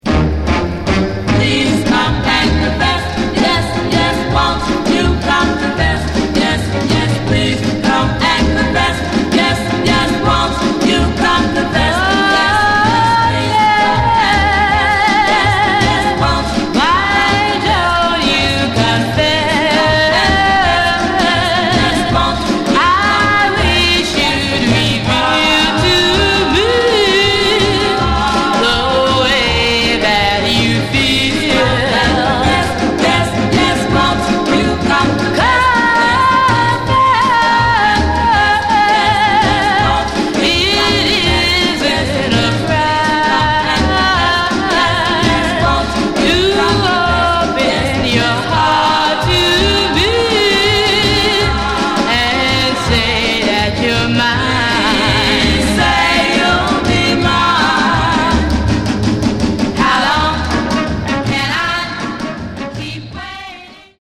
Genre: Girl Group